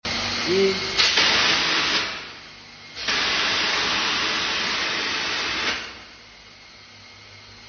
MK F02 X20 Fog Jet, comparison sound effects free download
comparison Mp3 Sound Effect MK-F02 X20 Fog Jet, comparison with co2 jet machine, similar effects, more convenient to use with, only need smoke liquid!